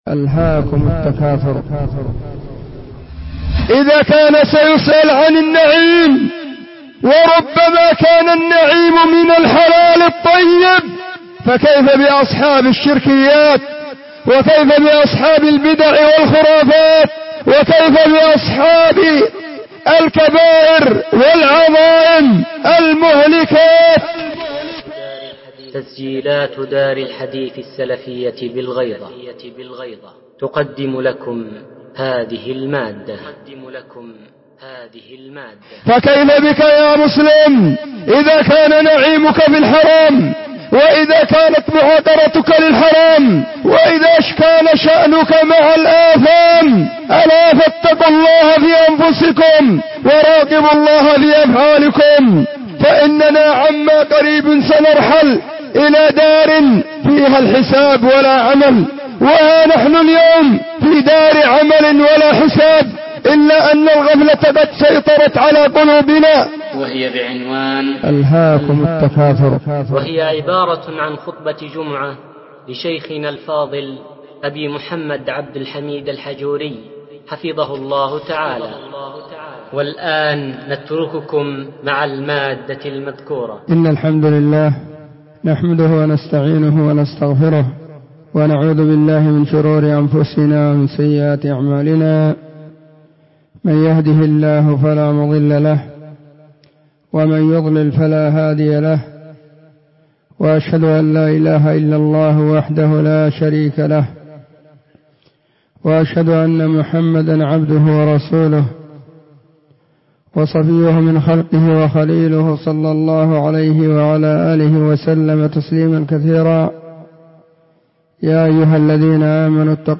📢 وكانت في مسجد الصحابة بالغيضة محافظة المهرة – اليمن.